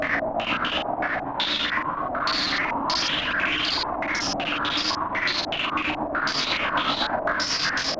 STK_MovingNoiseA-120_01.wav